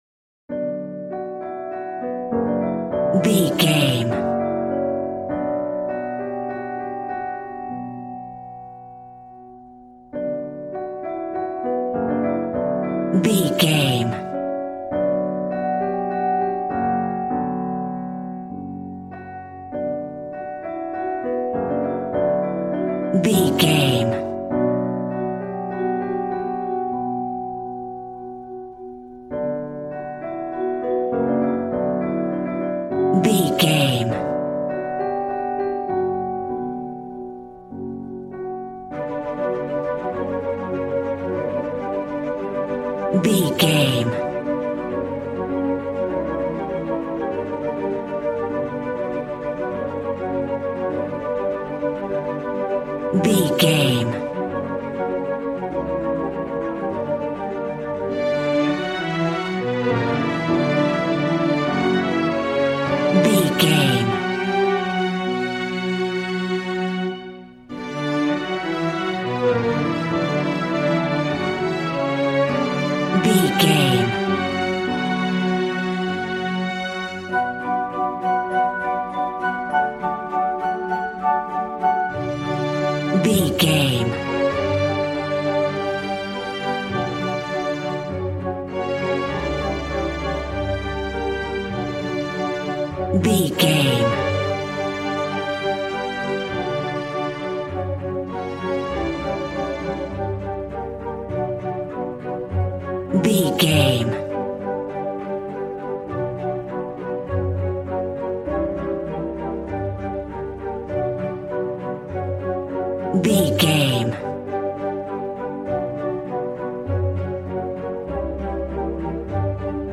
Regal and romantic, a classy piece of classical music.
Aeolian/Minor
regal
strings
violin
brass